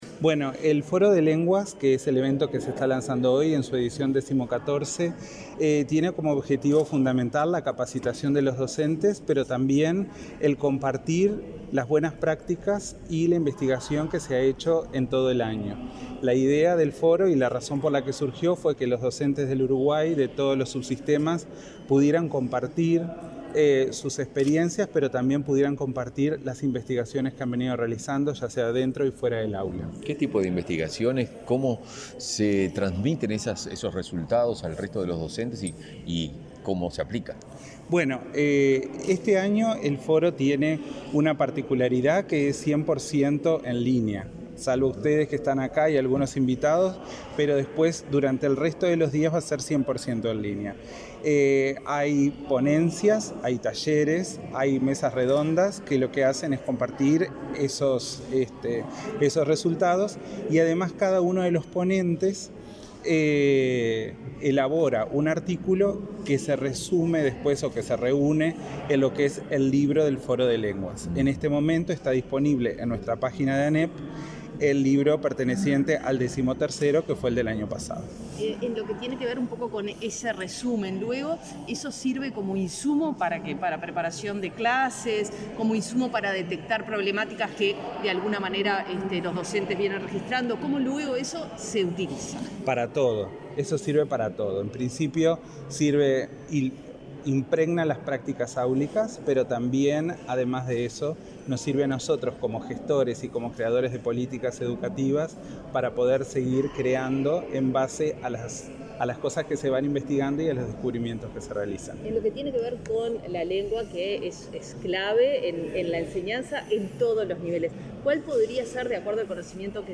Declaraciones a la prensa del director de Políticas Lingüísticas del Codicen, Aldo Rodríguez
Declaraciones a la prensa del director de Políticas Lingüísticas del Codicen, Aldo Rodríguez 15/10/2021 Compartir Facebook X Copiar enlace WhatsApp LinkedIn Tras participar en el acto de lanzamiento del 14.° Foro de Lenguas, este 15 de octubre, el director de Políticas Lingüísticas del Codicen efectuó declaraciones a la prensa.